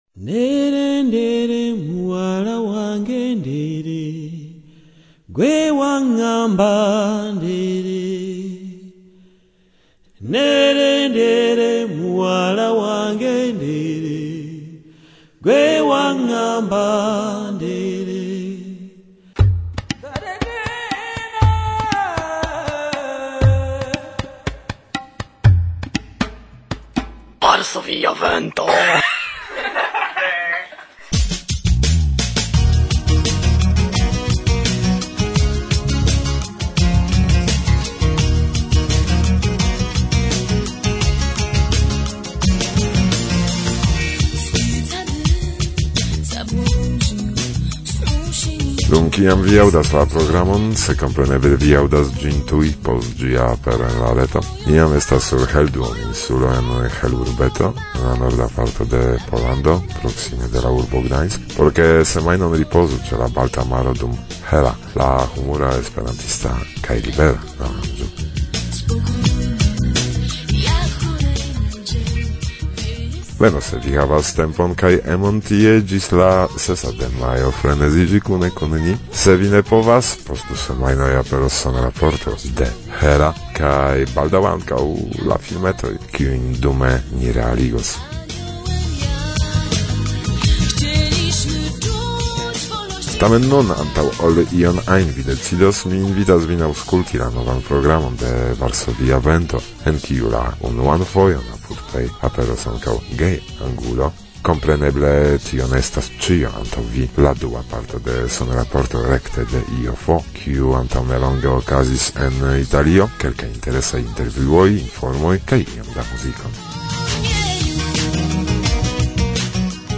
Sonraporto de la 31a IJF en Lignano Sabbiadoro